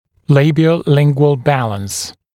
[ˌleɪbɪə(u)’lɪŋgwəl ‘bæləns][ˌлэйбио(у)’лингуэл ‘бэлэнс]язычно-губной баланс
labiolingual-balance.mp3